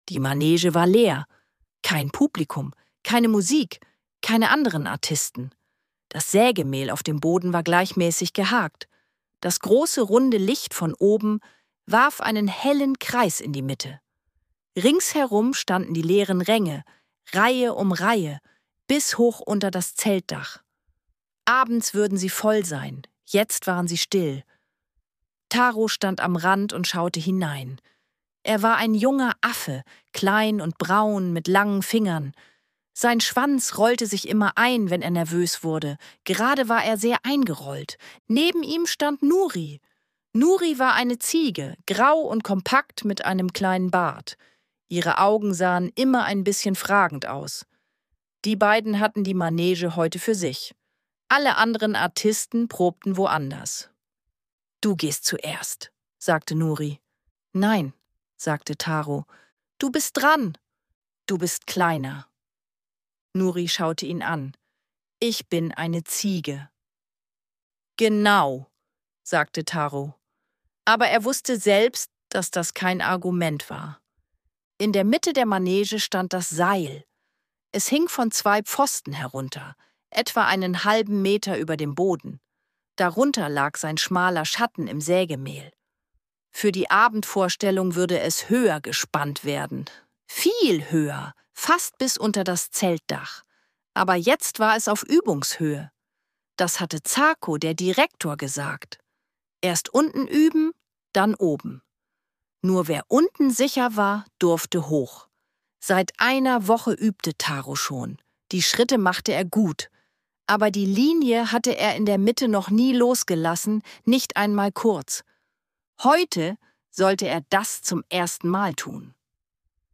Eine ruhige Abenteuergeschichte für Kinder über Mut und Vertrauen.
Ruhige Kindergeschichten zum Anhören